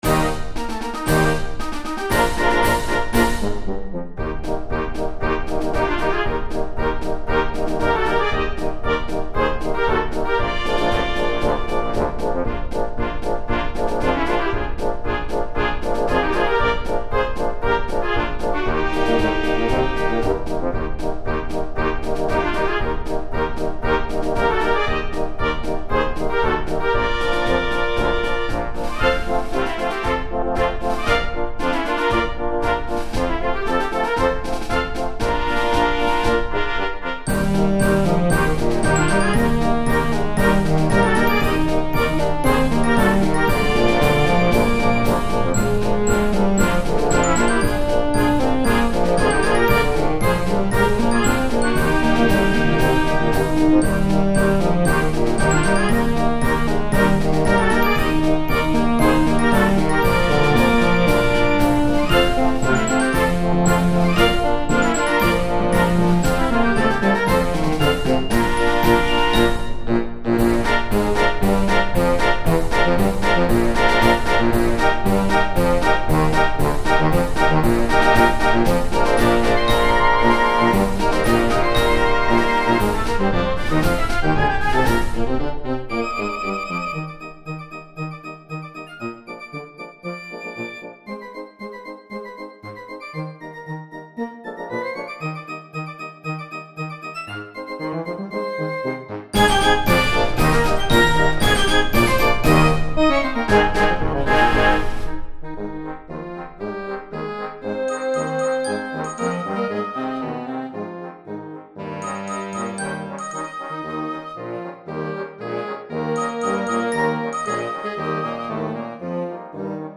I did not write any dotted 8th/16th notes. I wrote a 16th, two 16th rests and then the 4th sixteenth of the beat with house-top accents on the notes. NO TRIPLETS ALLOWED!
MARCH MUSIC; MILITARY MUSIC